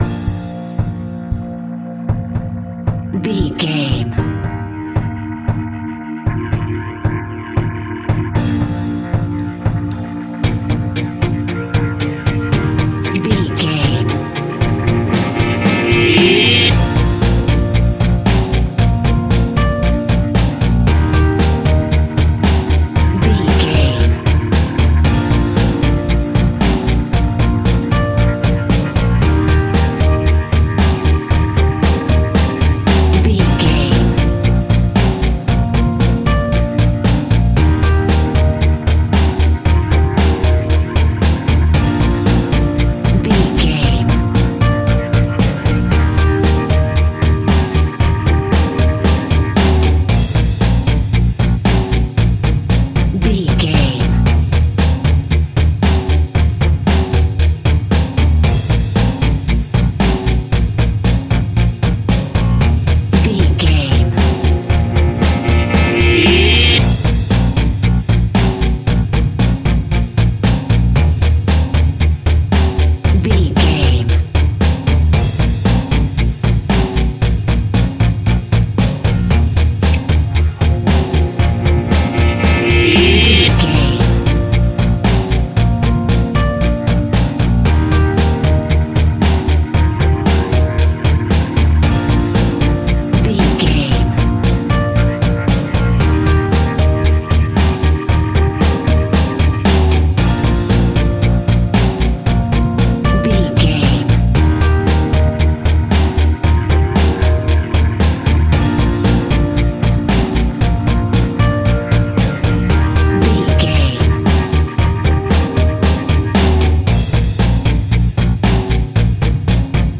Uplifting
Ionian/Major
B♭
pop rock
indie pop
fun
energetic
guitars
bass
drums
piano
organ